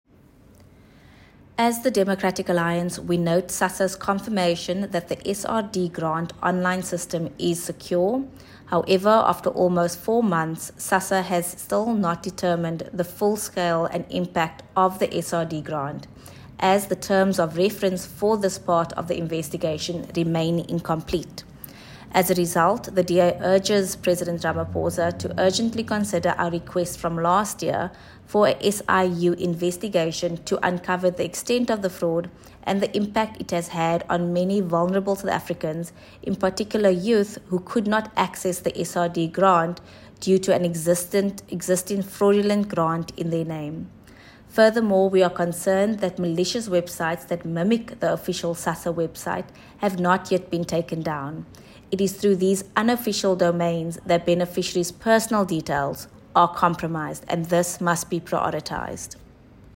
soundbite by Alexandra Abrahams MP.